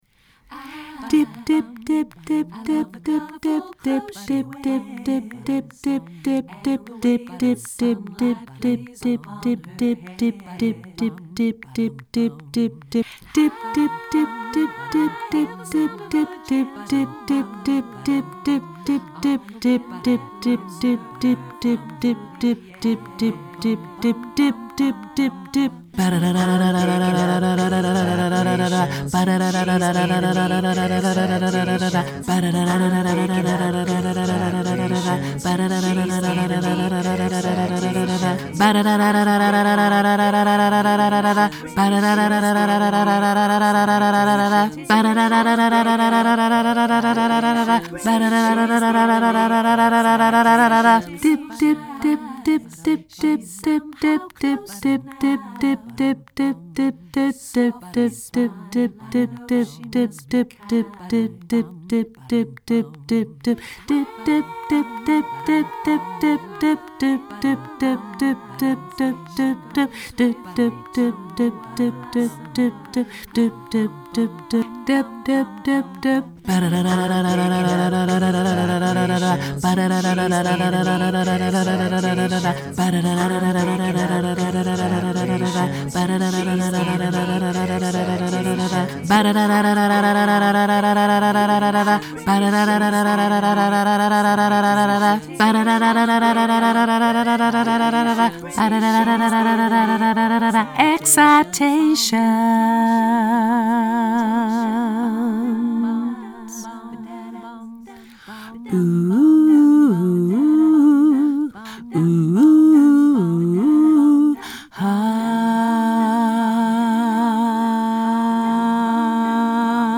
tenor laag